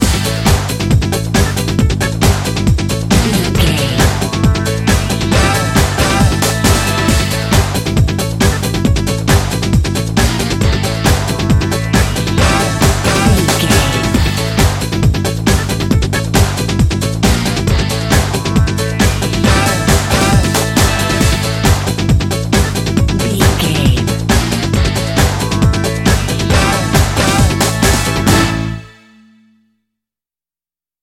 Ionian/Major
synthesiser
drum machine
Eurodance